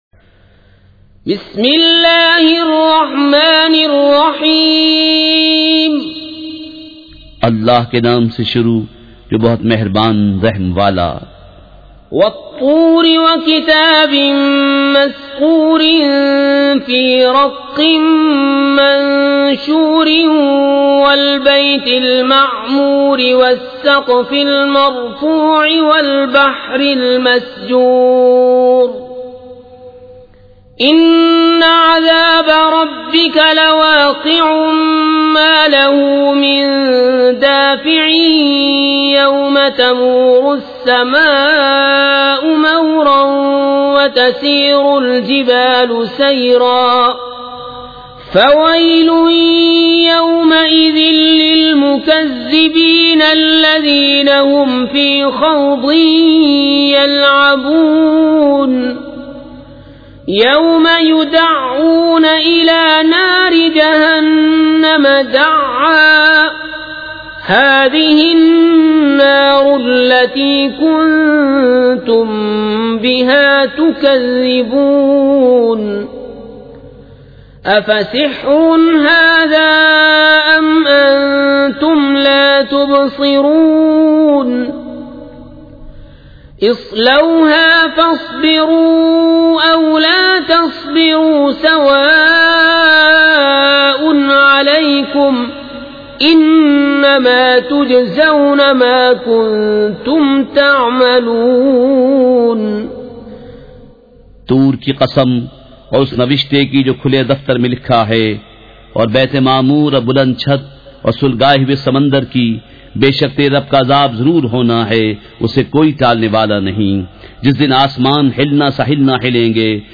نام سورۃ الطور مع ترجمہ کنزالایمان موضوع تلاوت آواز دیگر زبان عربی کل نتائج 1719 قسم آڈیو آڈیو ڈاؤن لوڈ MP 3 ڈاؤن لوڈ MP 4